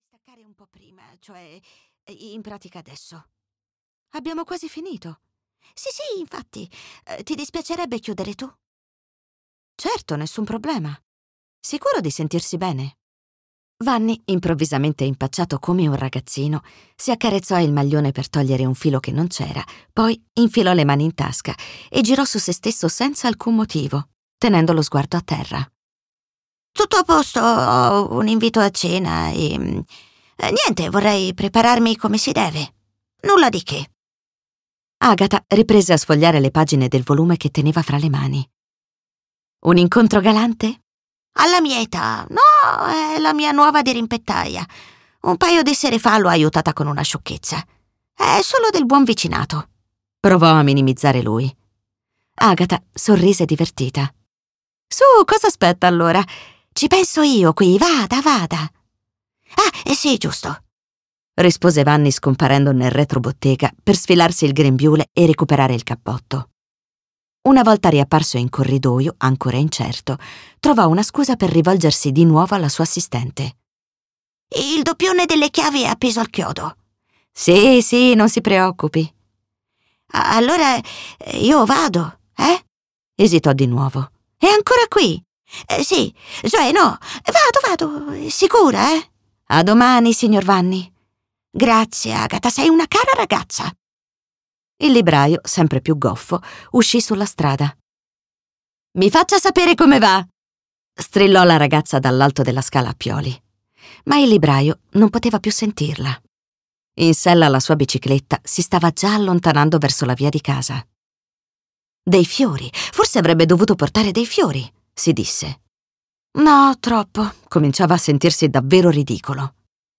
"I Buoni Propositi" di Sabrina Gabriele - Audiolibro digitale - AUDIOLIBRI LIQUIDI - Il Libraio